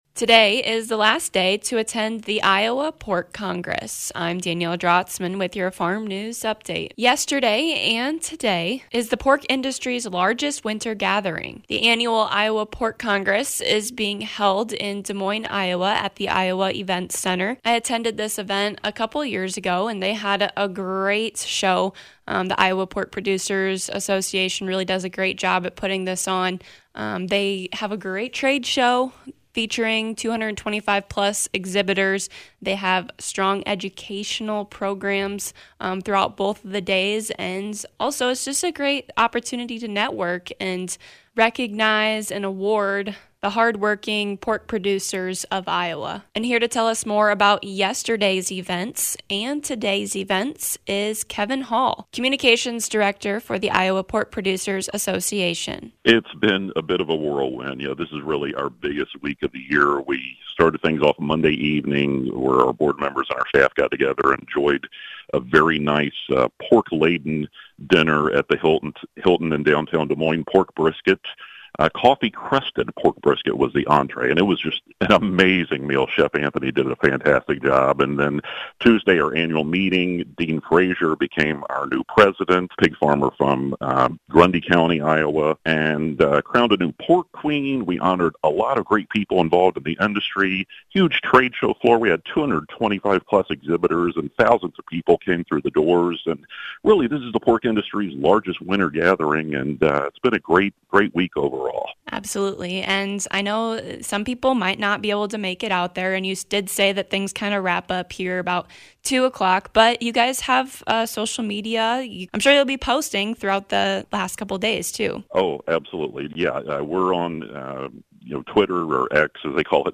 Today is day two of the Iowa Pork Congress in Des Moine Iowa.